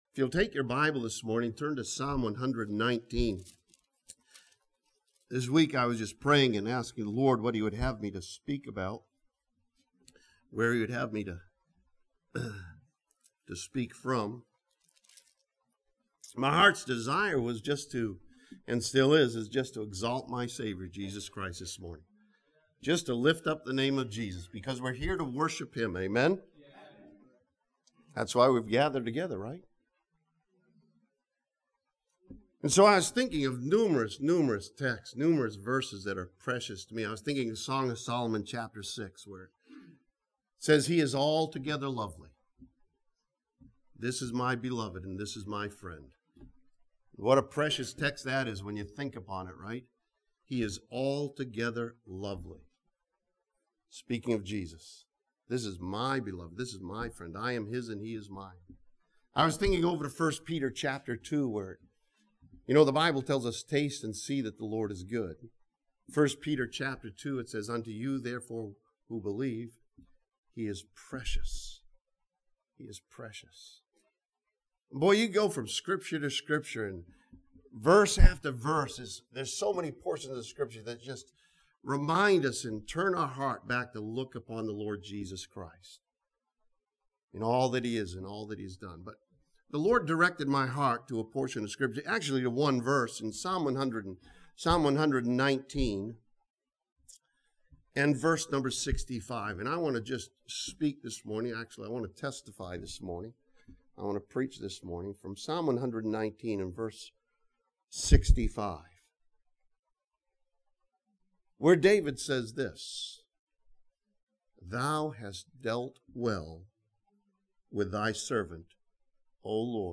This sermon from Psalm 119 remindes believers that God has dealt well with His servants and encourages believers to be thankful.